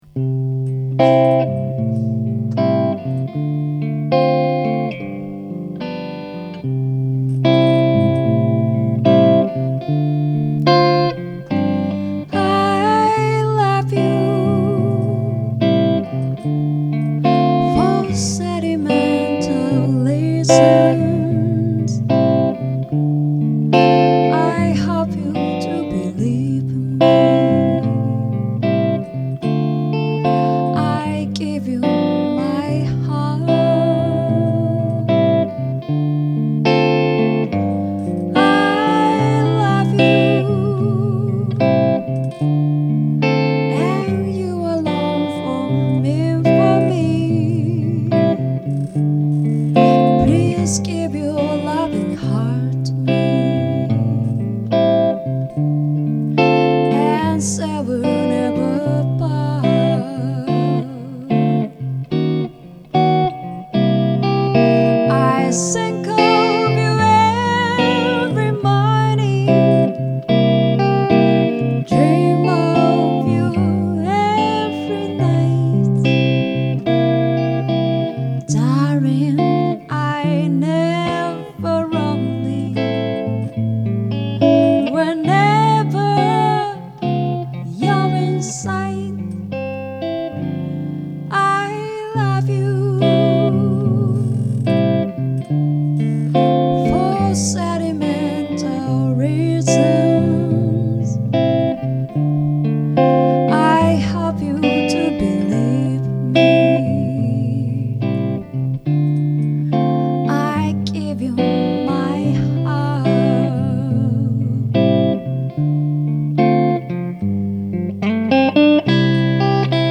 335を弾いた、昨年のクリスマスのライブより